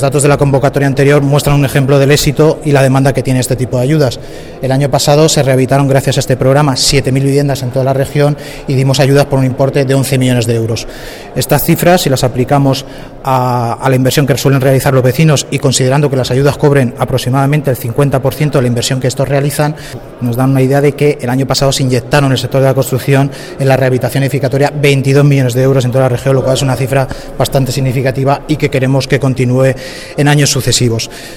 El director general de Vivienda y Urbanismo, José Antonio Carrillo, ofrece cifras del programa de rehabilitación edificatoria del año 2016 en Castilla-La Mancha.